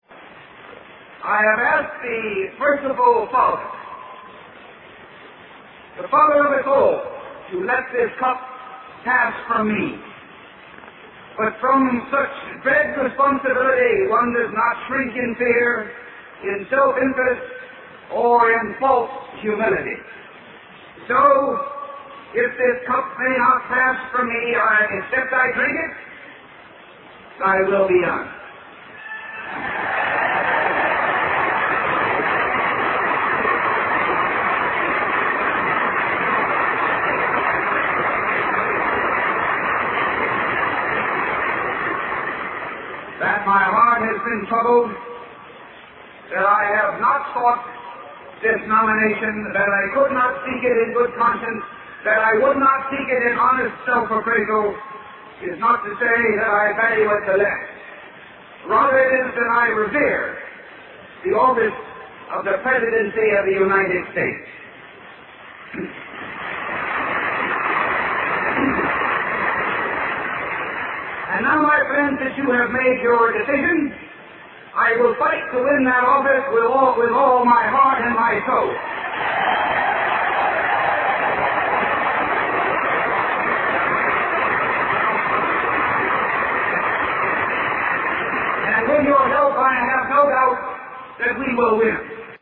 经典名人英语演讲(中英对照):Presidential Nomination Acceptance Speech 2